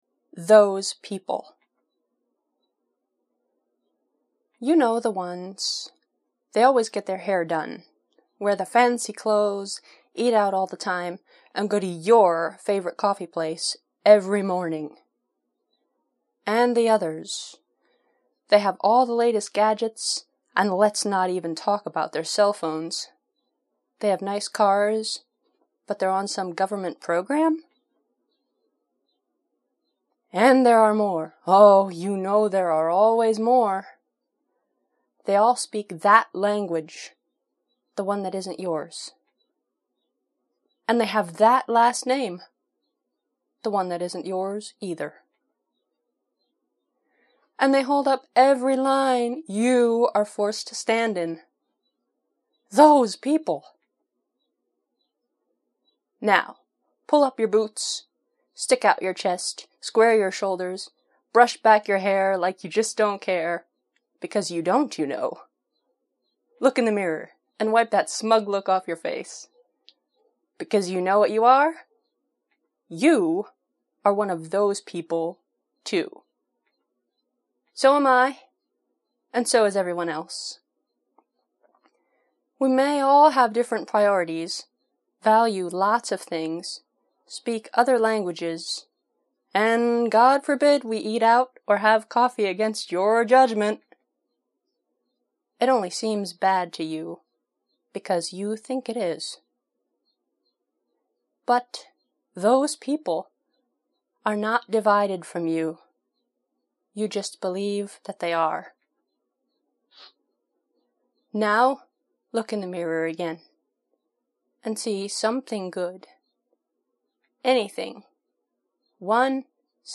“Those People!” Mp3 Poem